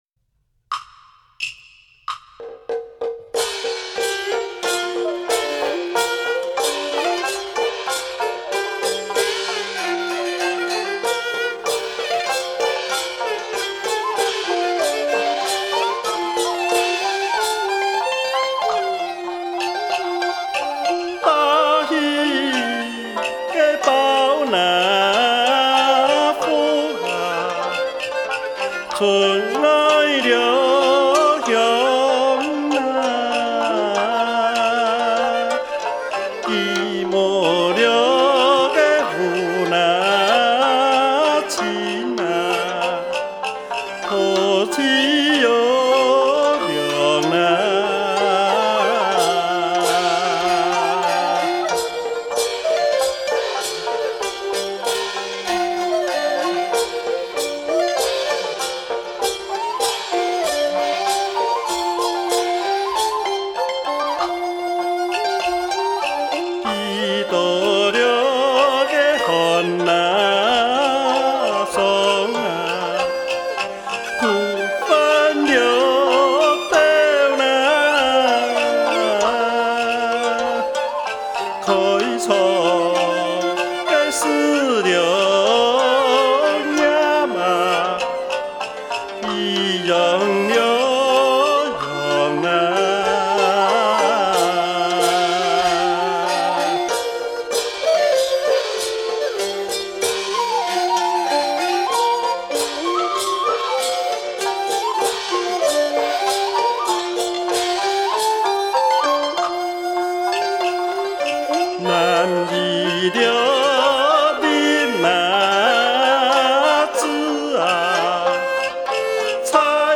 CD1：傳統客家歌謠 CD2：傳統客家歌謠